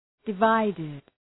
{də’vaıdıd}